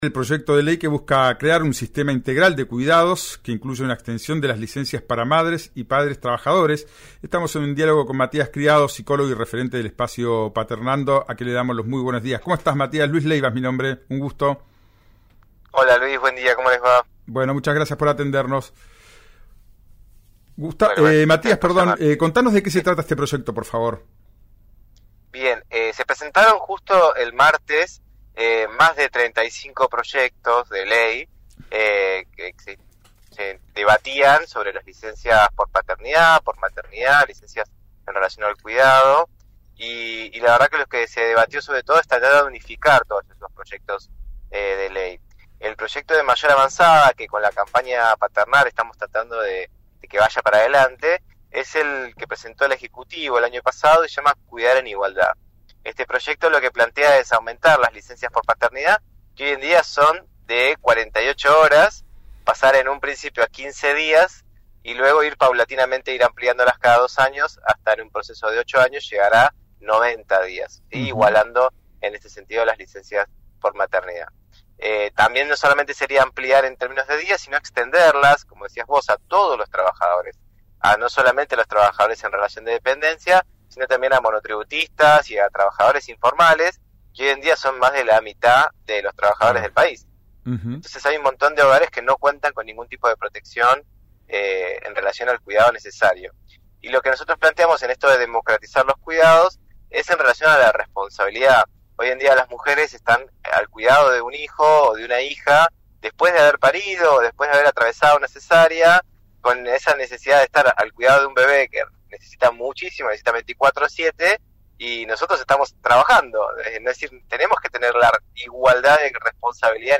en el programa «Ya es tiempo» de RIO NEGRO RADIO.